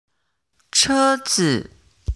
「e」が入った単語を声調別でリストアップしたので、音声を聞いてネイティブの発音をマネしてみて下さい。
「車子 chē zi」の発音